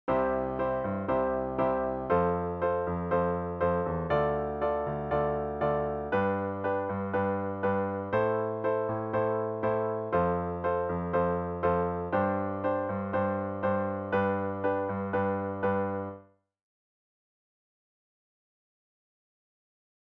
(Click “listen” to hear a basic keyboard rendition of the chords.
1. VERSE: C  F  Dm  G  Am  F  Gsus  G (I  IV  ii  V  vi  IV  Vsus4  V)
Description: Both the verse and the chorus are in C major, and use mainly the same chords but in a different order.